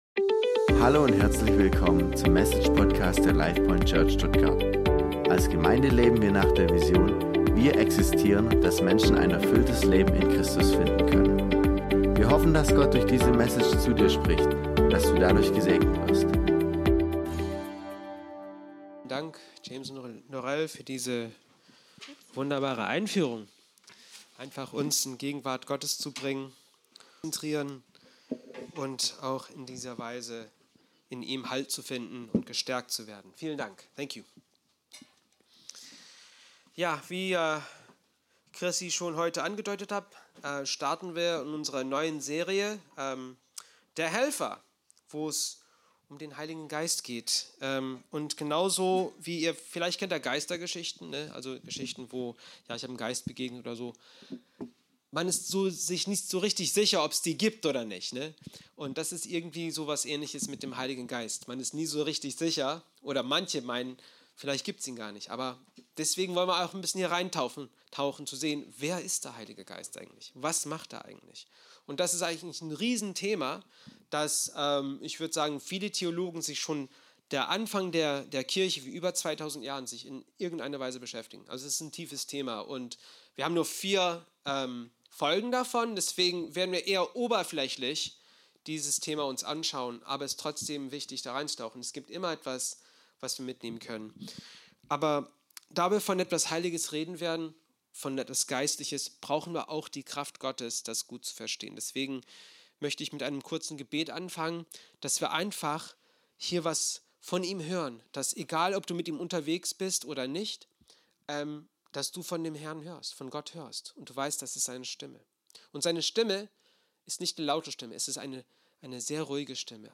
Diese Predigt lädt dazu ein, den Heiligen Geist ganz neu kennenzulernen – nicht als theologisches Konzept, sondern als lebendige Realität, die jeden Christen begleitet und befähigt.